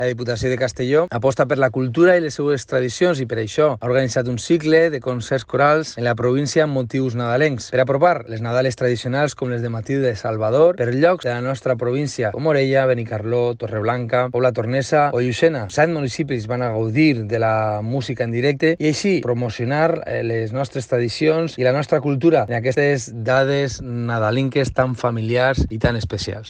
Alejandro-Clausell-diputado-de-Cultura-anuncia-el-ciclo-de-conciertos-corales.mp3